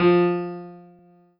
piano-ff-33.wav